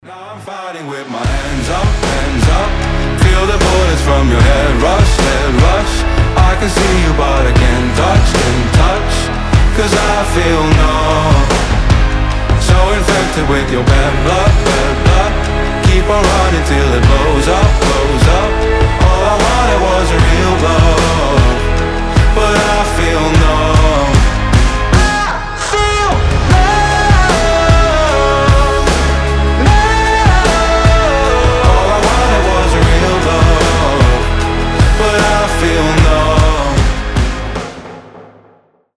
• Качество: 320, Stereo
красивый мужской голос
мелодичные
Pop Rock
alternative